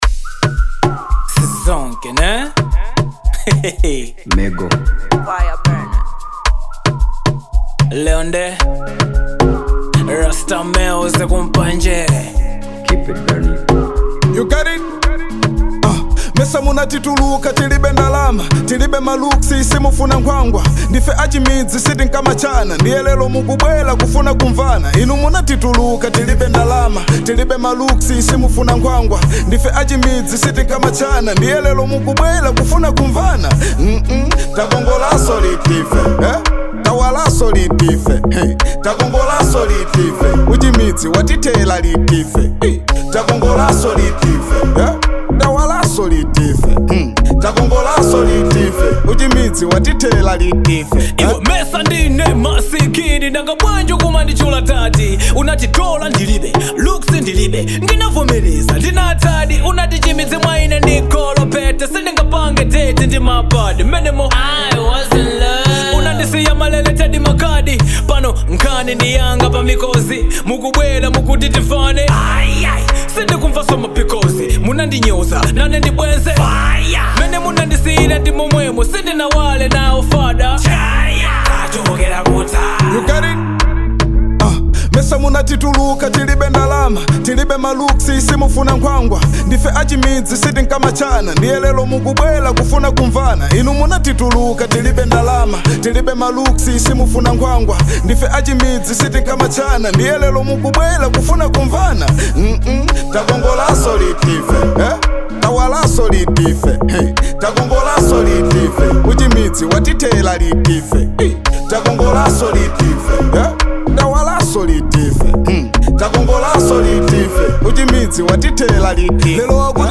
Genre : Hiphop/Rap